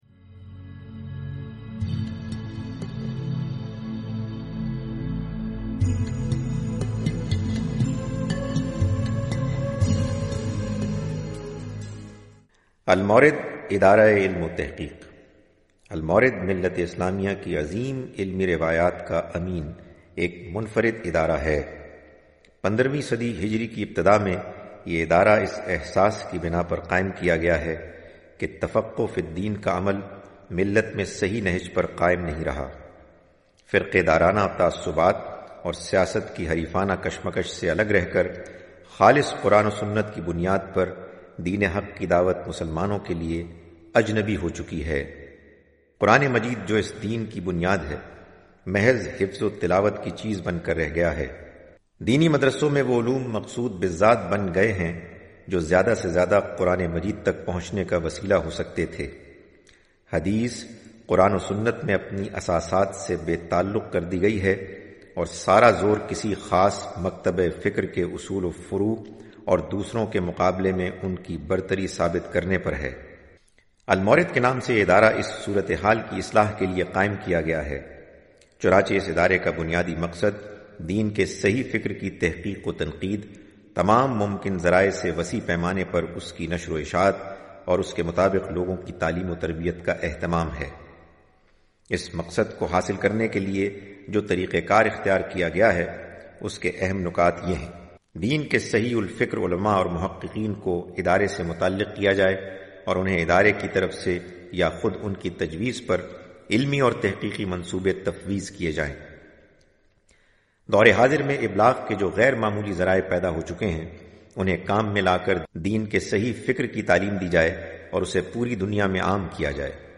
Al-Mawrid Documentary (Urdu)